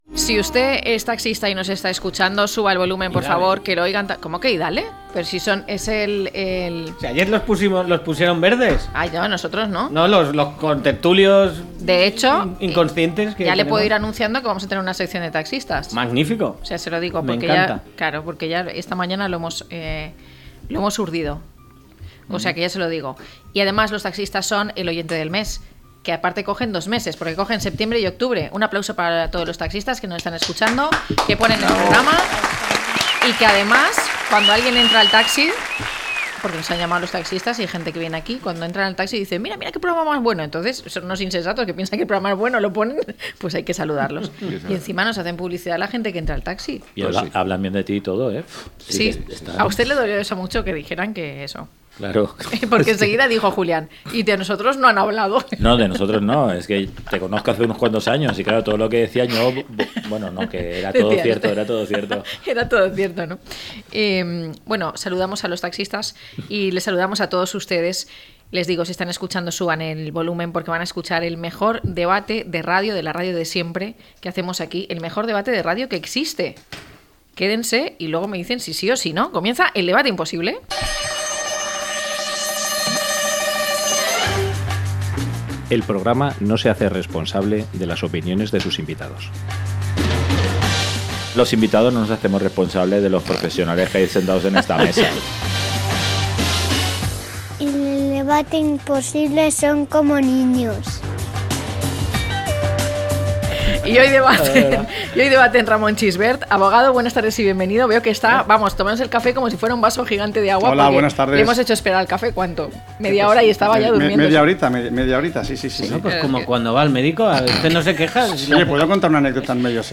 Los invitados del debate imposible discuten el tema.